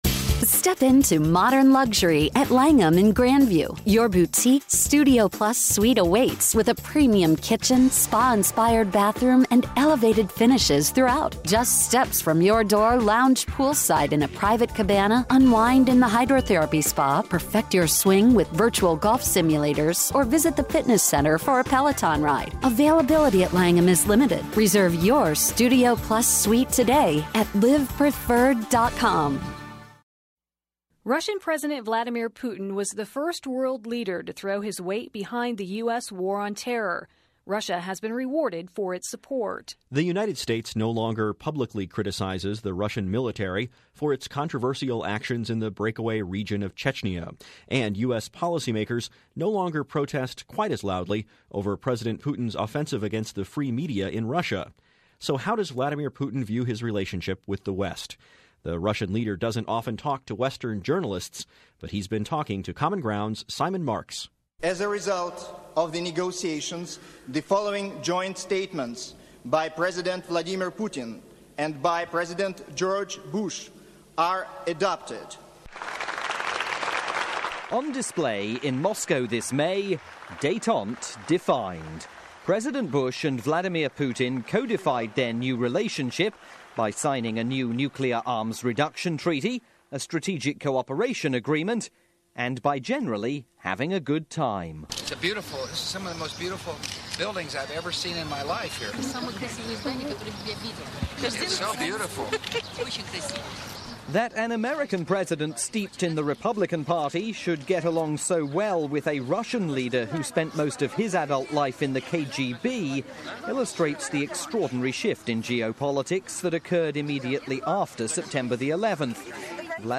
July 2003 - Vladimir Putin Interview
This report aired originally on "Common Ground Radio" in the USA.